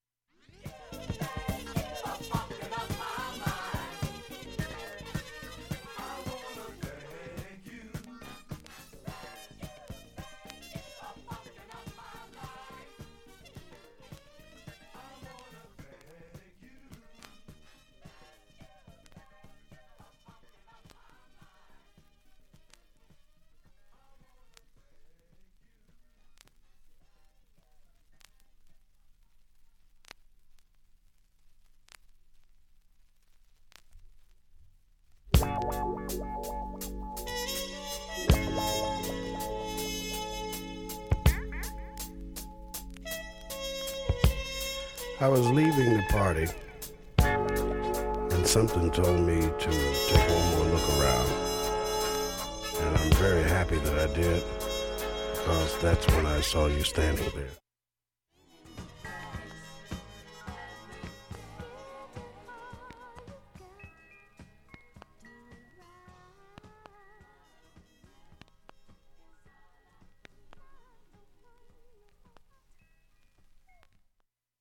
盤面きれいで音質良好全曲試聴済み。
A-2始めに４０秒の間に周回プツ出ますがかすかです。
かすかなプツが7回出ます。
メロウ・フュージョン・ディスコ